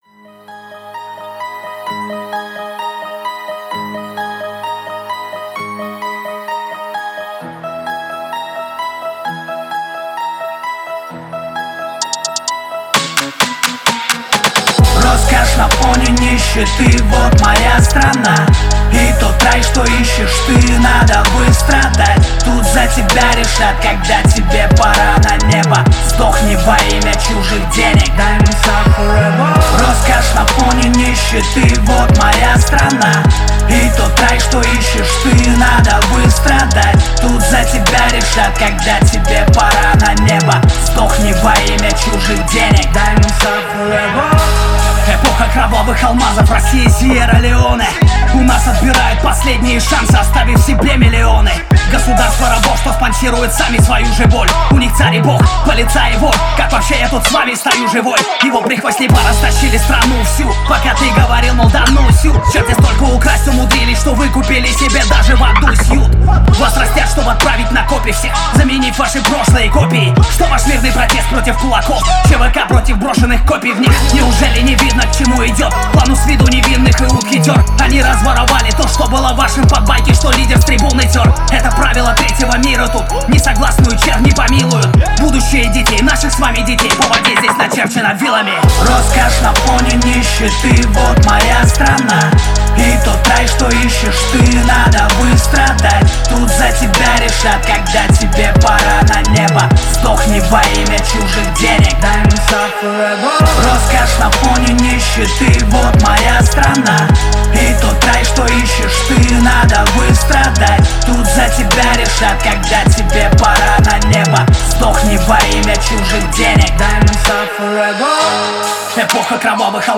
это мощный хип-хоп трек